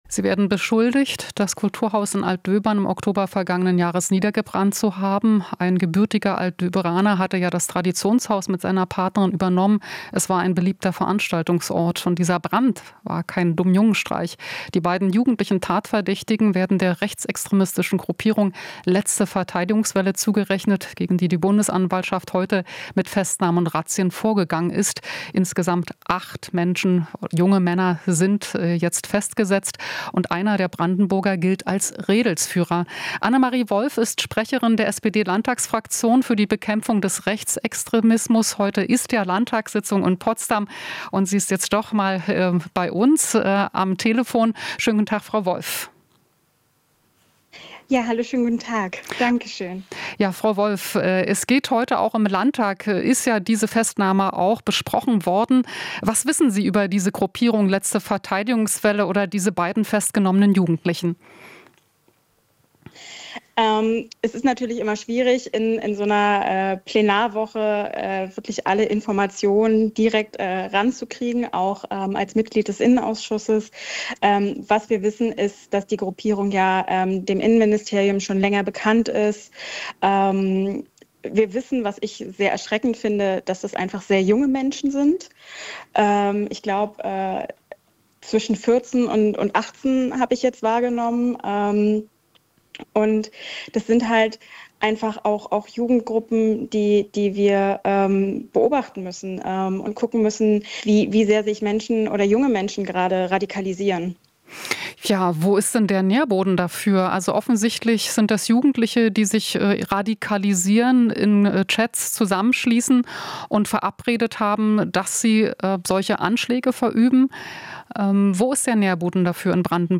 Interview - Wolff (SPD): Radikalisierung Jugendlicher stärker beobachten